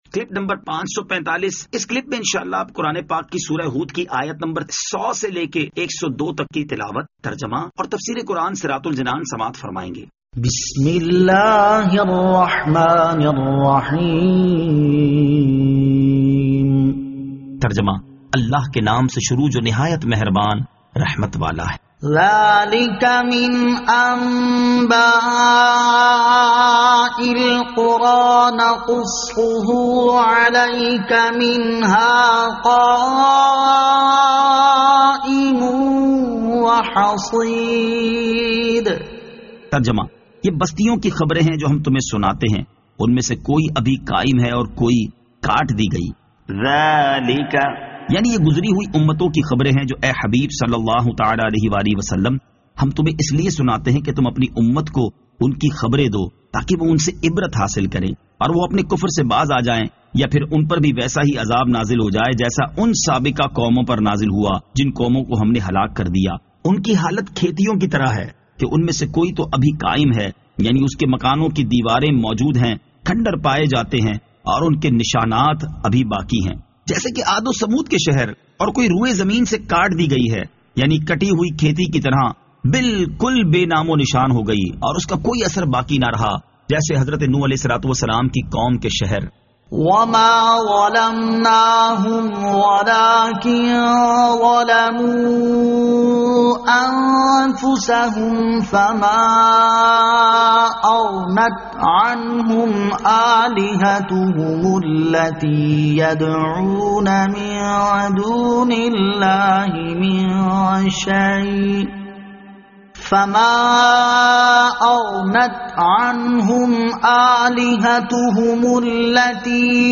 Surah Hud Ayat 100 To 102 Tilawat , Tarjama , Tafseer